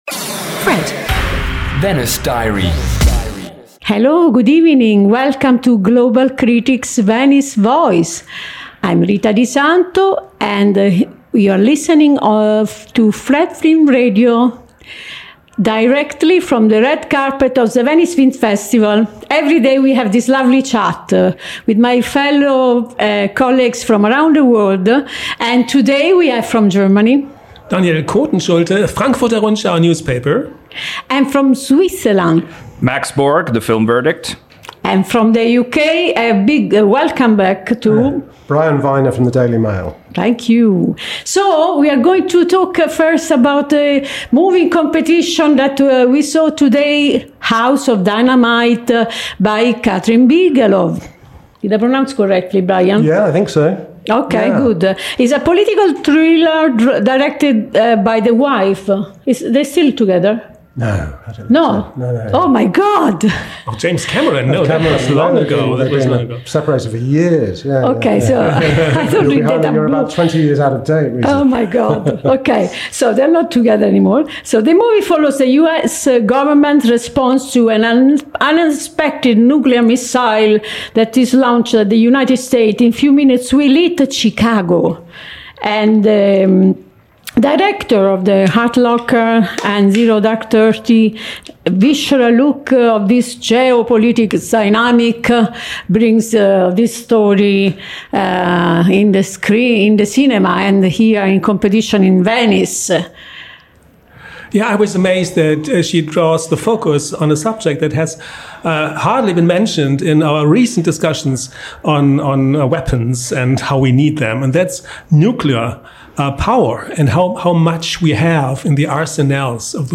Each episode takes listeners inside the Festival with exclusive and thoughtful conversations with leading international film critics, and in-depth analysis of the year’s most anticipated films.